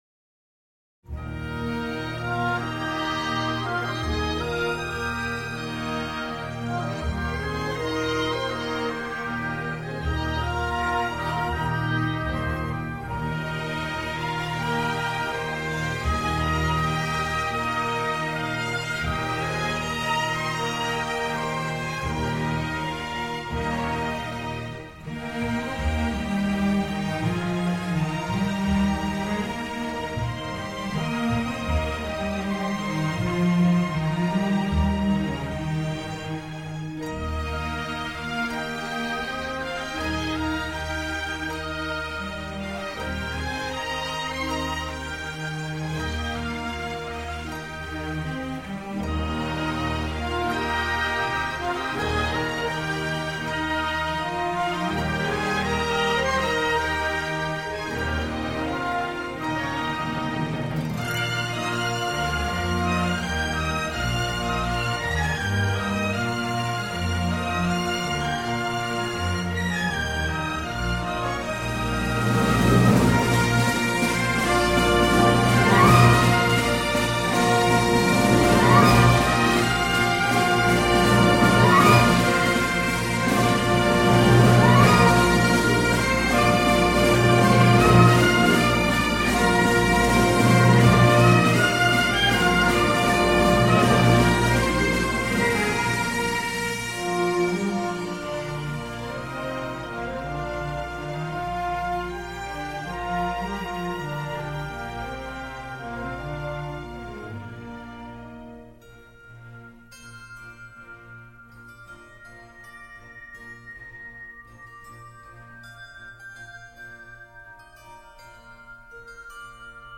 C’est une méditation où la musique respire de l’intérieur.